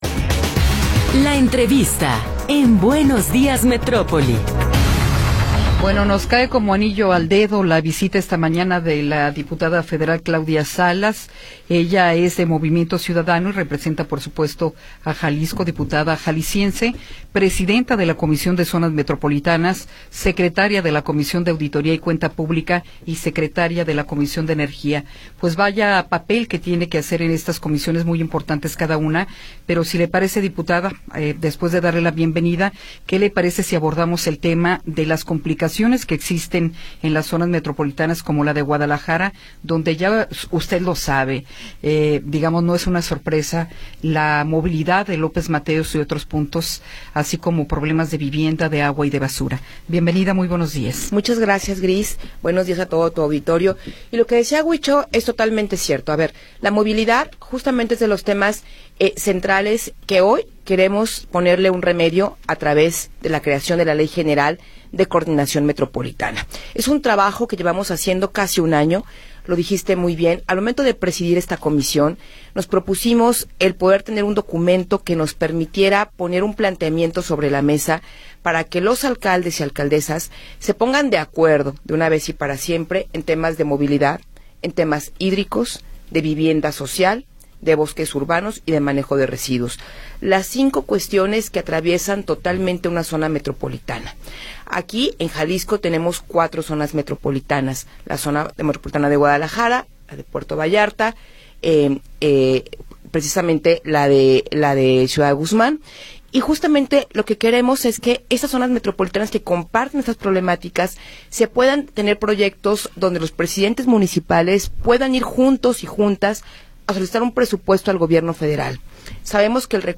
Entrevista con Claudia Salas Rodríguez